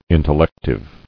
[in·tel·lec·tive]